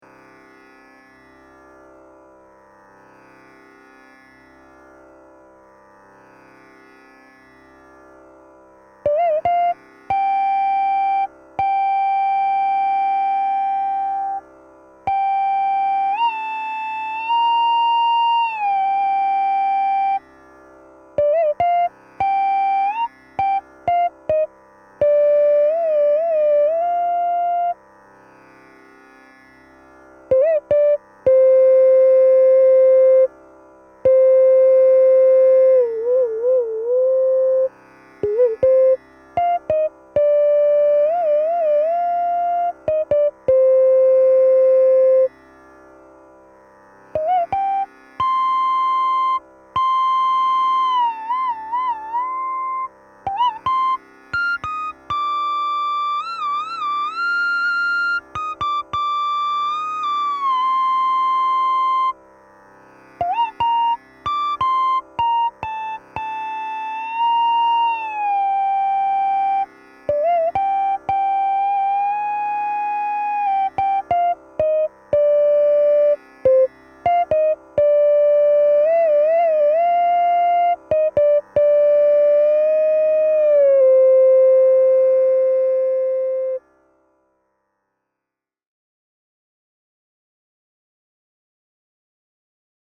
And this one has very minimal "editing" of the tune - i mean i got the melody in one cut (almost).